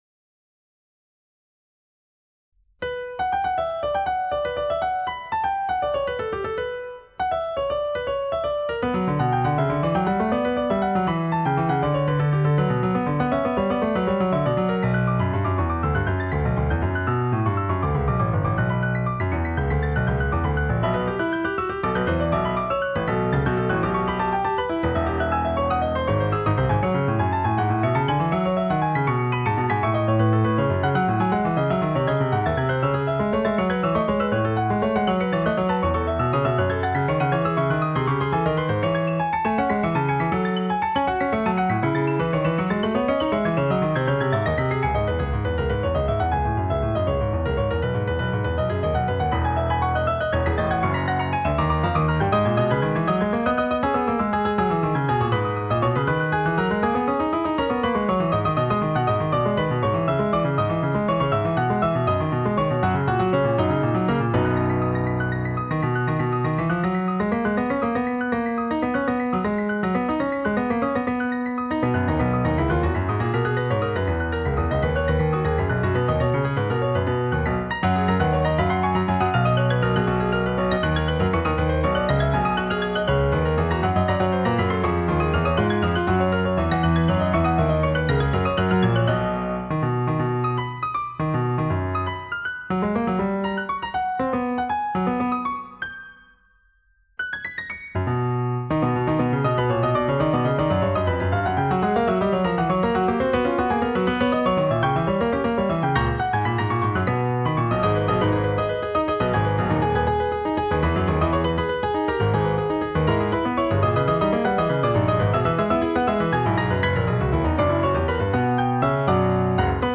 Fugue